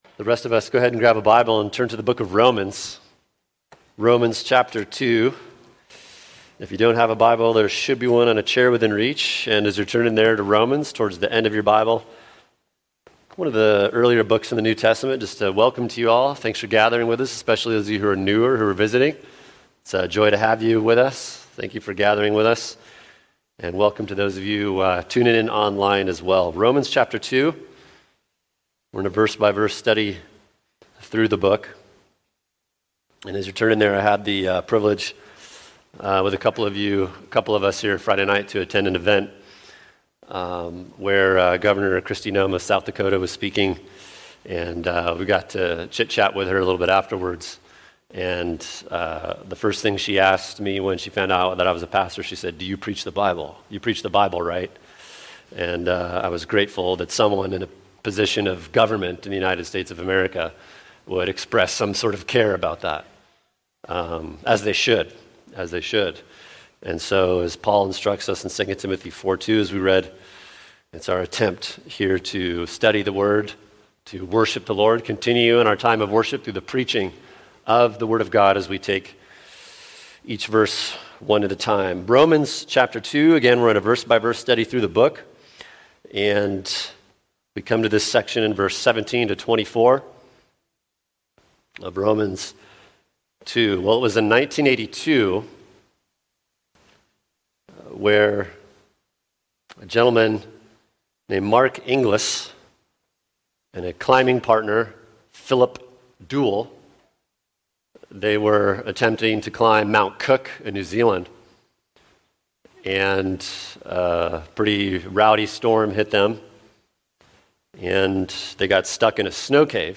[sermon] Romans 2:17-24 Privileges & Responsibilities | Cornerstone Church - Jackson Hole